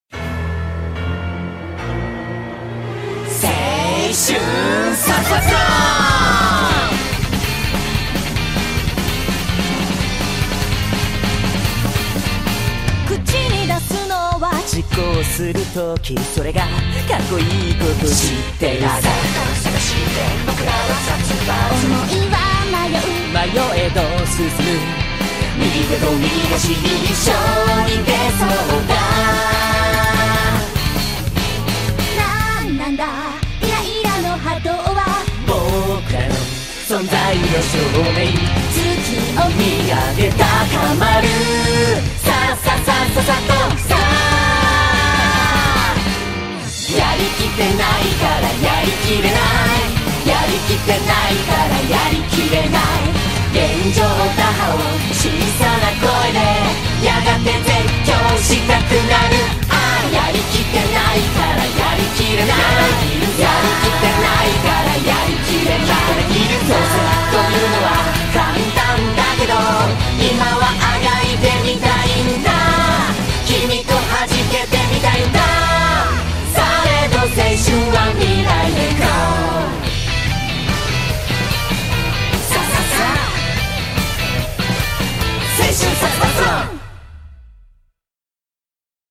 BPM73-146
Audio QualityPerfect (High Quality)
[Girls' Week] - Many female vocalists are featured.